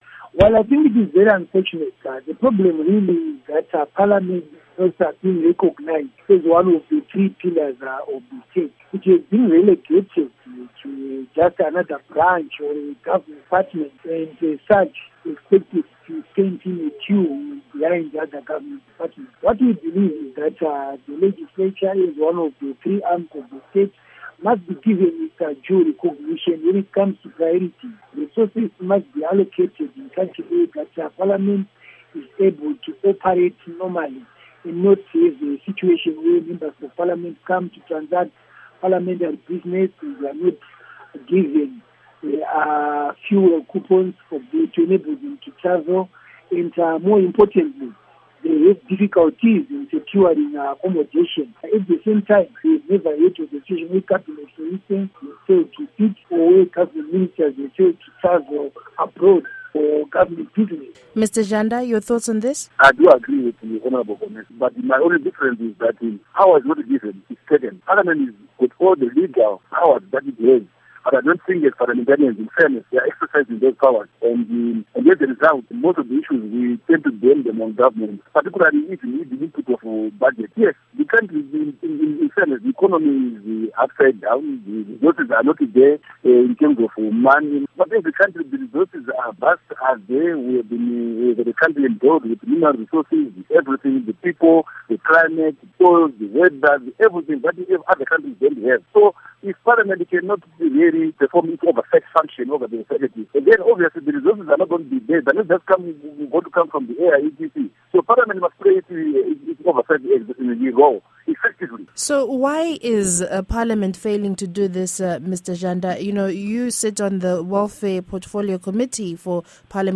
Panel Discussion with Paddy Zhanda & Innocent Gonese